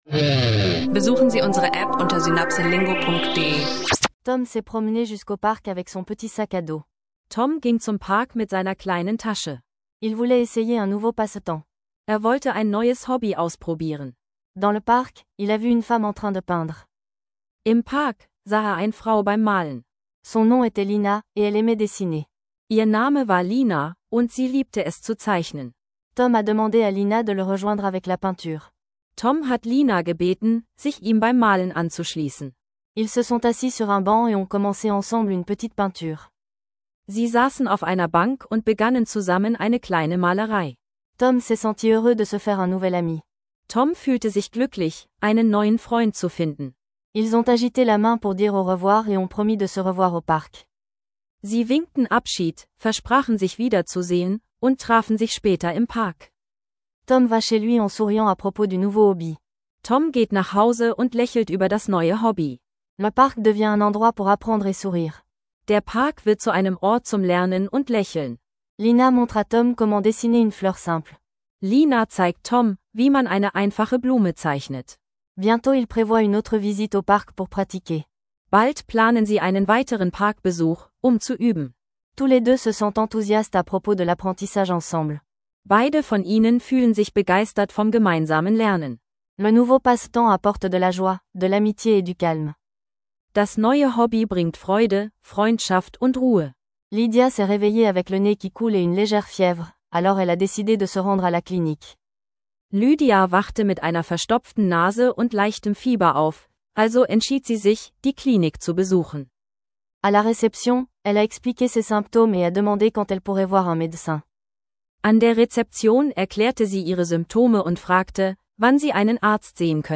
In dieser Folge des Französisch lernen Podcast entdecken wir Alltagsdialoge rund um Hobby, Gesundheit, Festivals und Nachrichten – perfekt für Anfänger und Fortgeschrittene, die Französisch lernen leicht gemacht suchen.